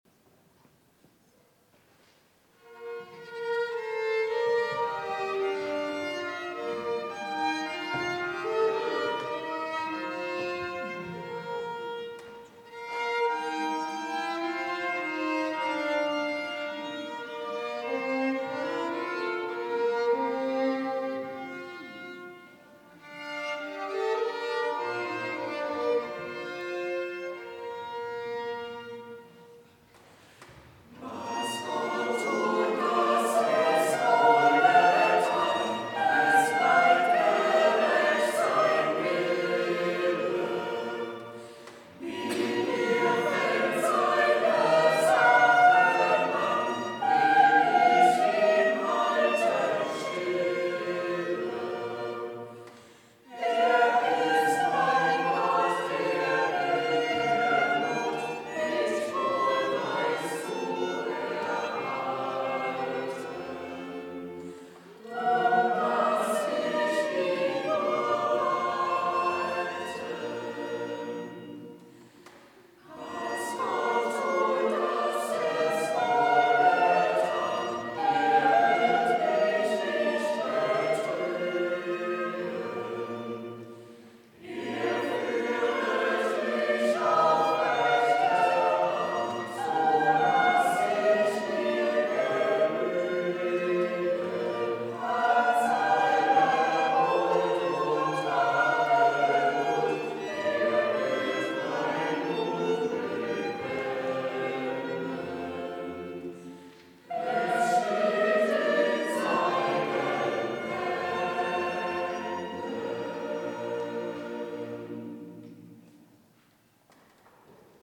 Was Gott tu, das ist wohl getan... Chor der Ev.-Luth. St. Johannesgemeinde Zwickau-Planitz
Audiomitschnitt unseres Gottesdienstes vom 19. Sonntag nach Trinitatis 2025.